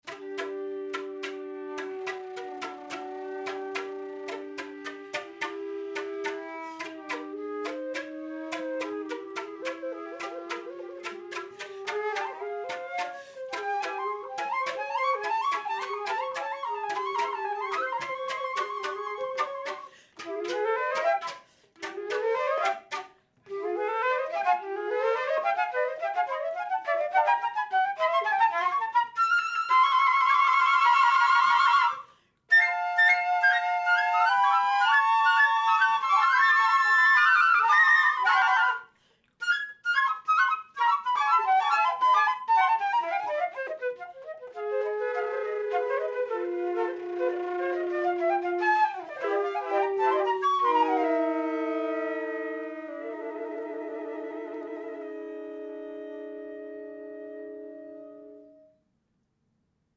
This recent composition is written for four C flutes. Night Bloom, is a one minute soundscape intended to capture the image of the blooming evening primrose.